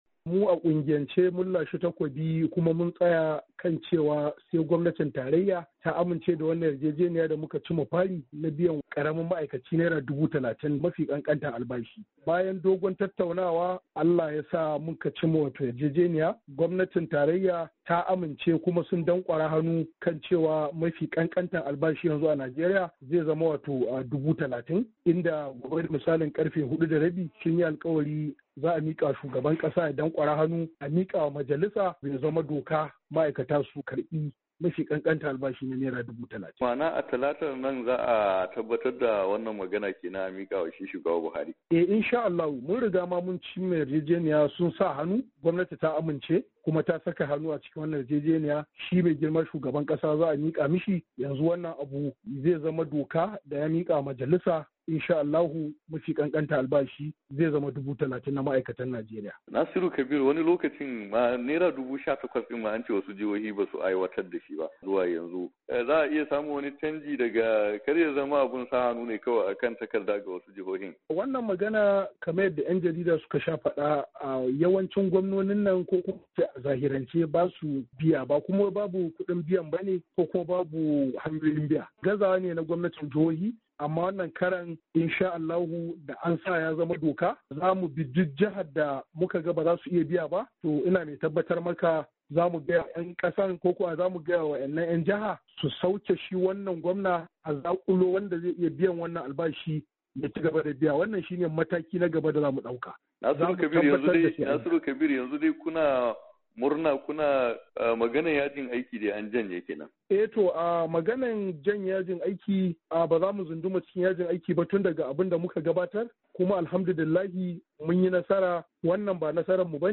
ABUJA. HIRA DA KUNGIYAR MA AIKATA